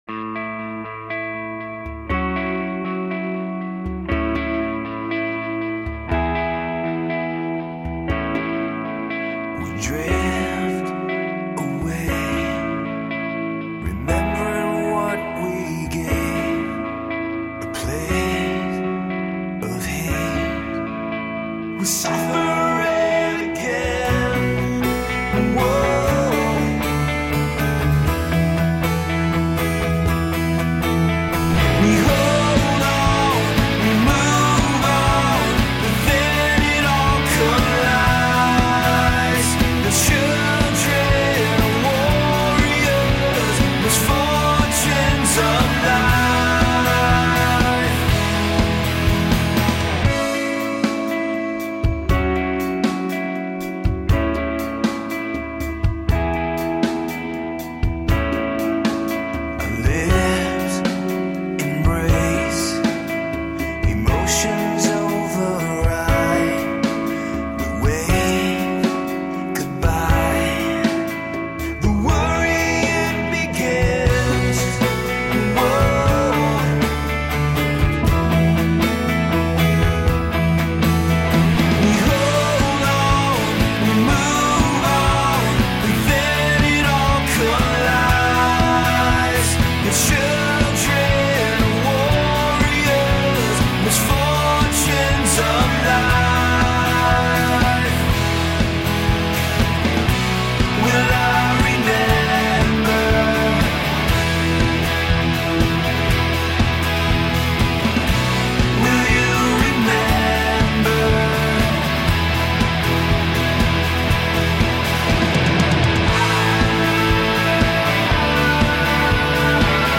soaring pop arena rock sound
Tagged as: Electro Rock, Rock